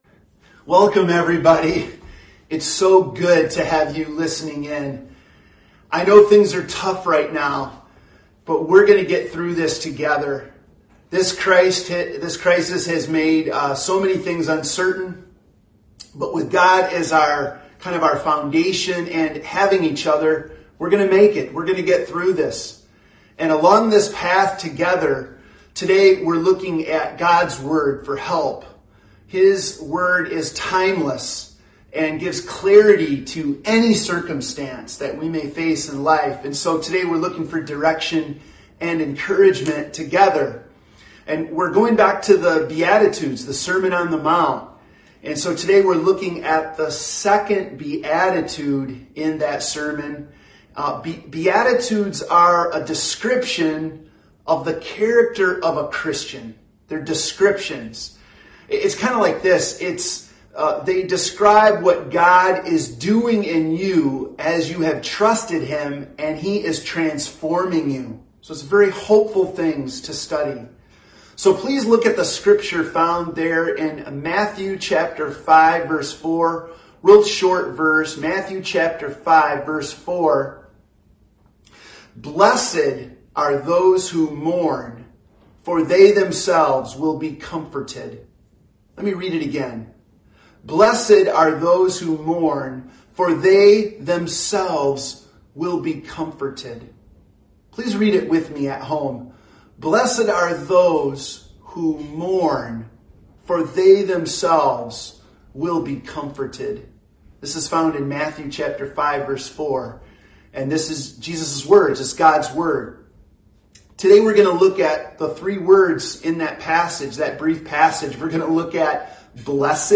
Service Type: Sunday Morning Preacher